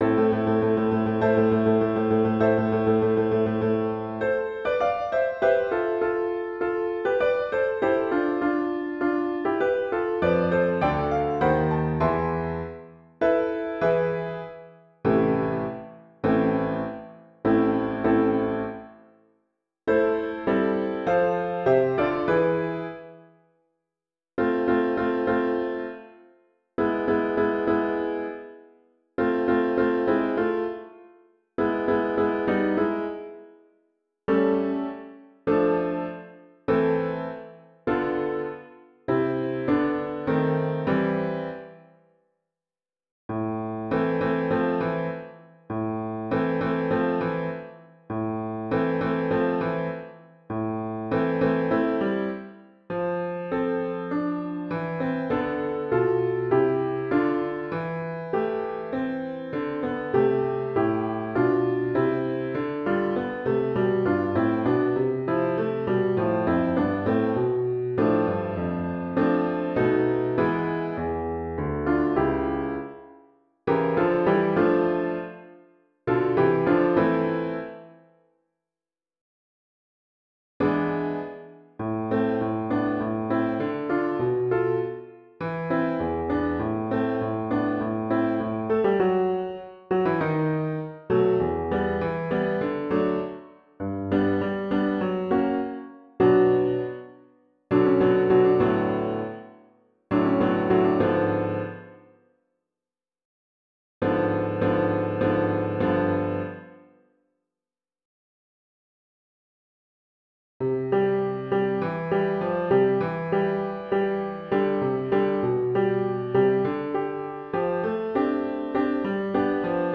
Portnoff_Opus-18 > 바이올린 | 신나요 오케스트라
반주